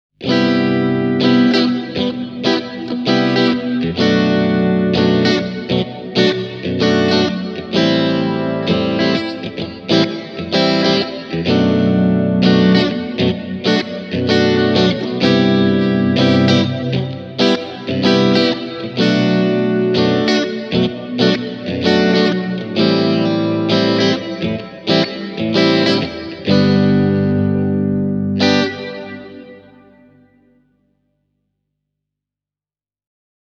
Plate Modissa kaikuun on lisätty chorusefekti:
digitech-hardwire-supernatural-e28093-plate-mod.mp3